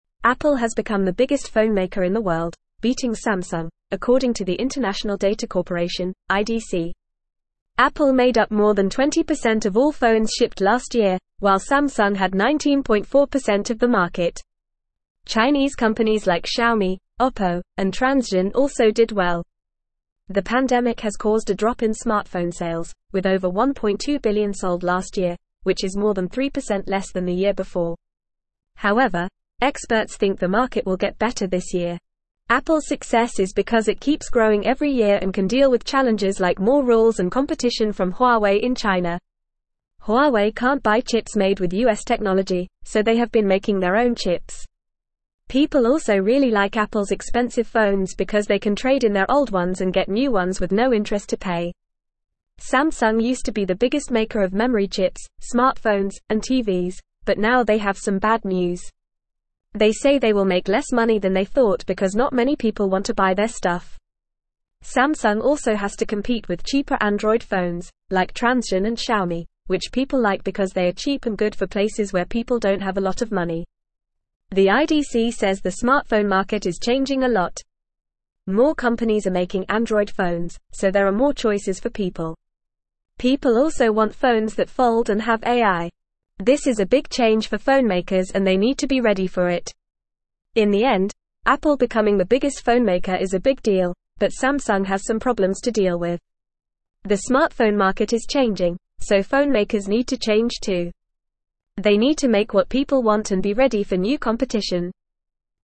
Fast
English-Newsroom-Upper-Intermediate-FAST-Reading-Apple-Surpasses-Samsung-as-Worlds-Largest-Phonemaker.mp3